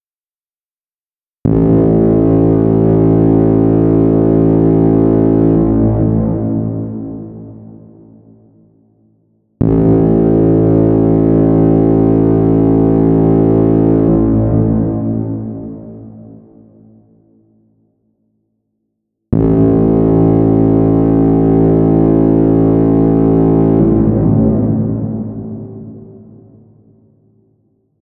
Alarm-Horn.ogg